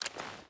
Footsteps / Water / Water Walk 2.wav
Water Walk 2.wav